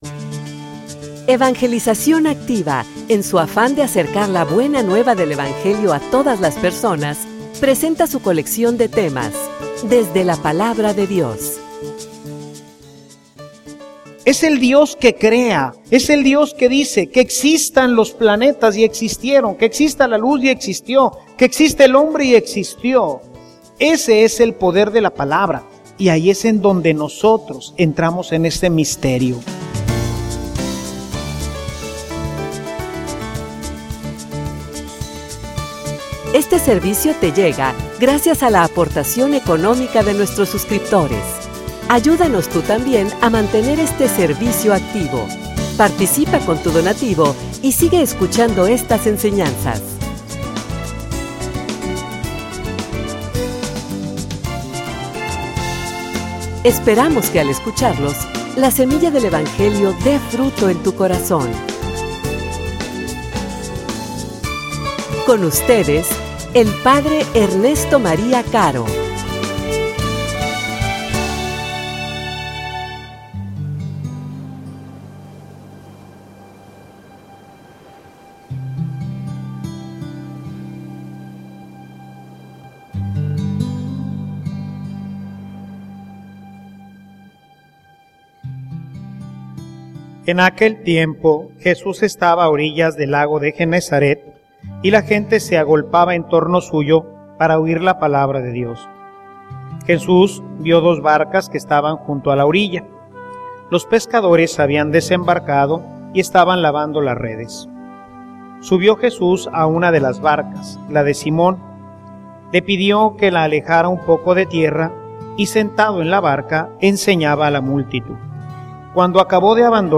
homilia_Amor_y_confianza_en_la_Palabra.mp3